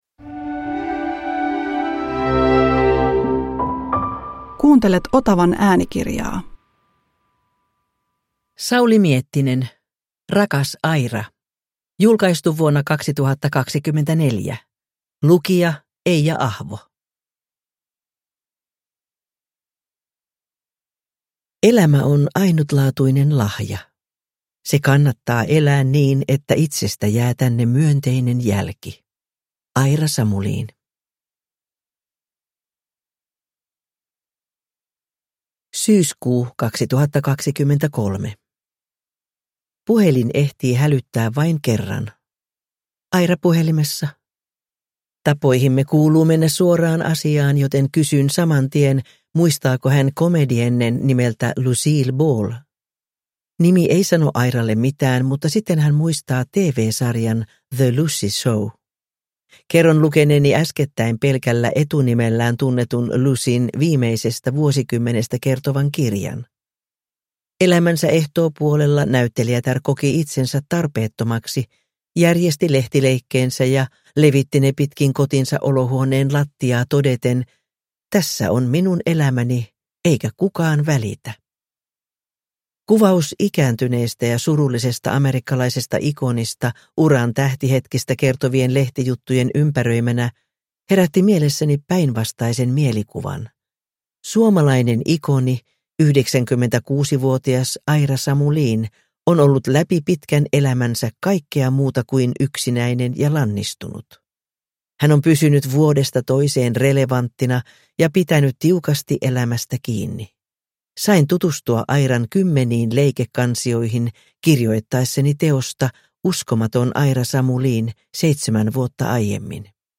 Rakas Aira (ljudbok) av Sauli Miettinen | Bokon